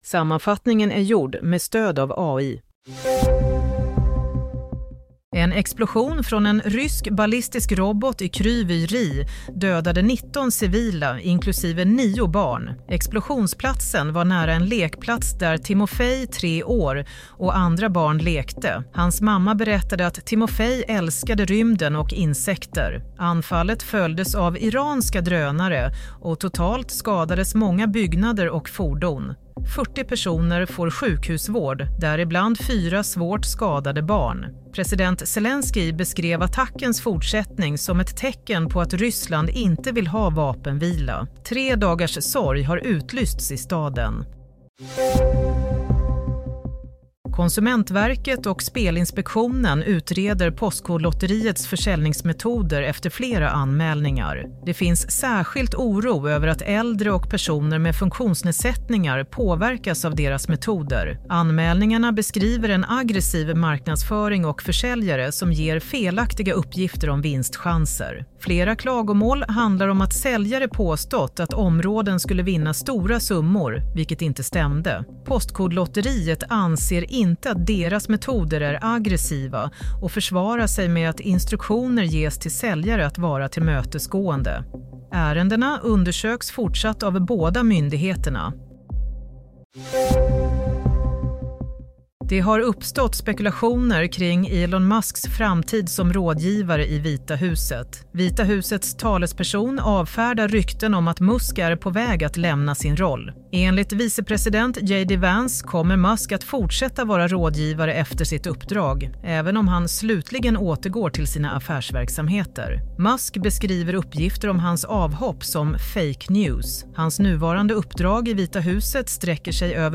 Nyhetssammanfattning - 6 april 07:00